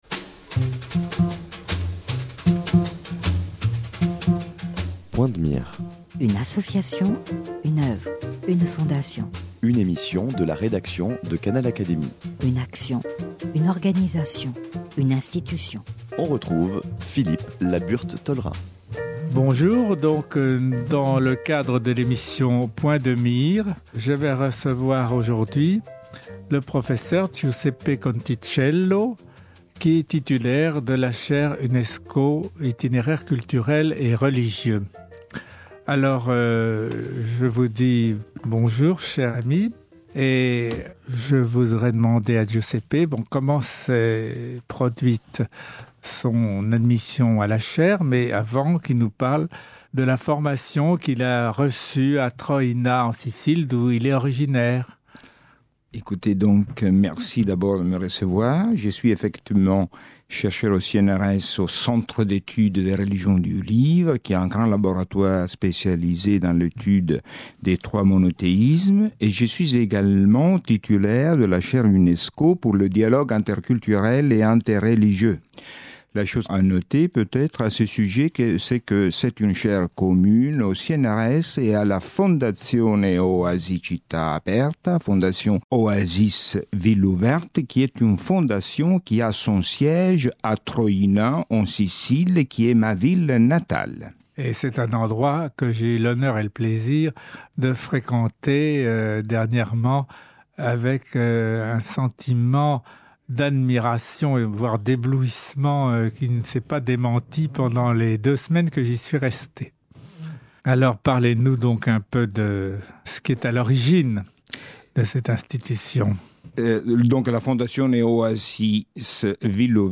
Découvrez celle de Troina, en Sicile, pour l’étude comparative des religions et des cultures. Interview